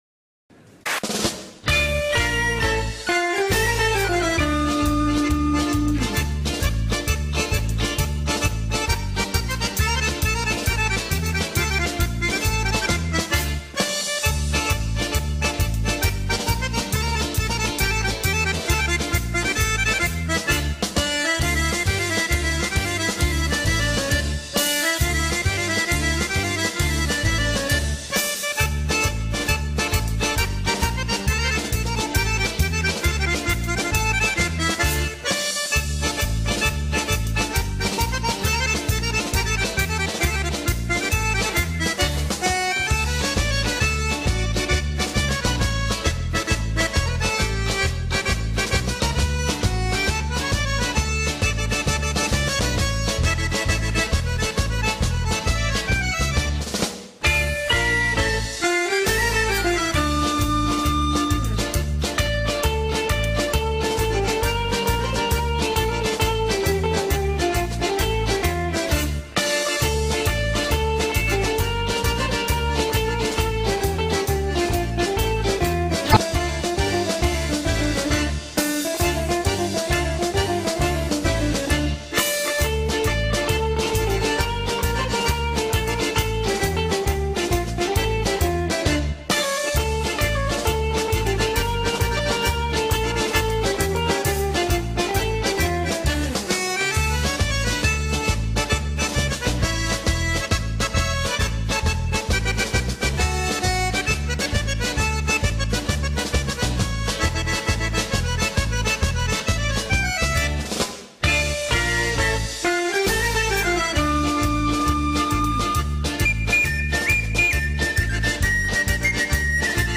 Tarantela
La tarantela és un ball popular d'un país d'Europa. És una dansa amb tempo ràpid i compàs de 6/8. Sol interpretar-se amb instruments com l'acordió i acompnyar-se amb panderetes, castanyoles i palmes.